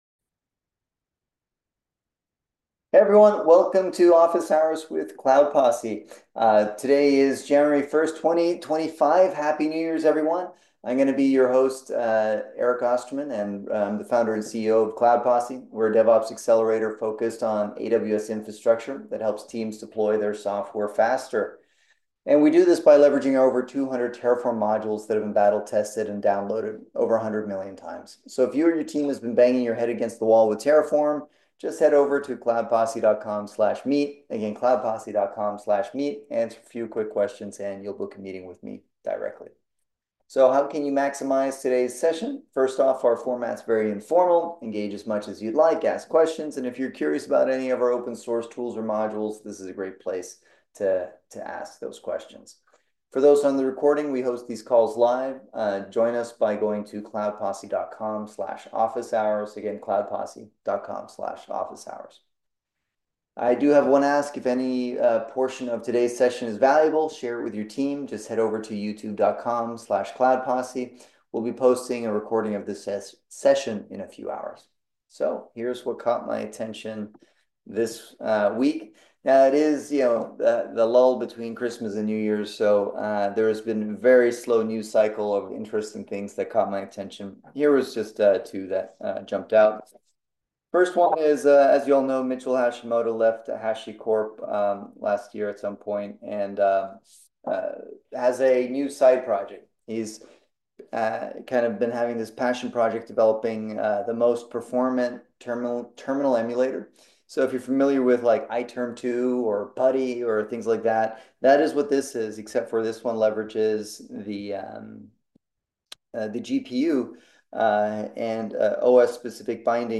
Cloud Posse holds public DevOps “Office Hours” every Wednesday at 11:30am PST to answer questions on all things DevOps/Terraform/Kubernetes/CICD related.